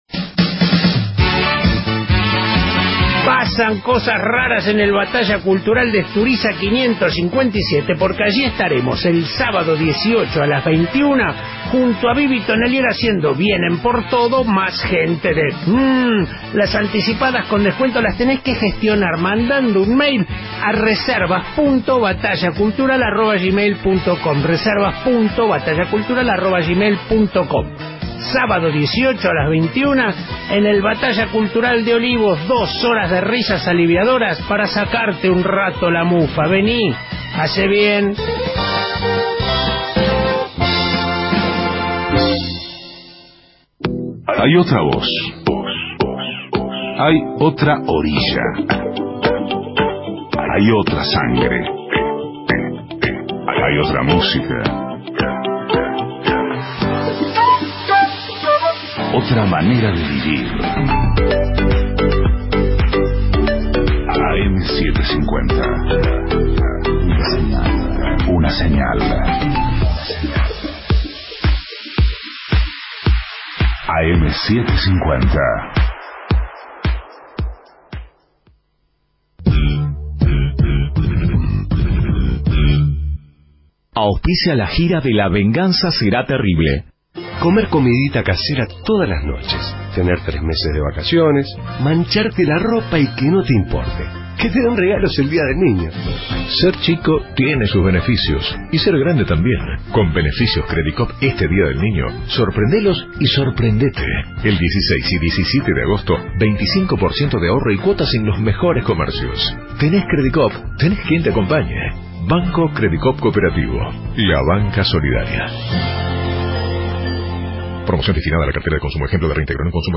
Estudios Radio AM 750, Buenos Aires